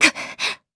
Seria-Vox_Damage_jp_02.wav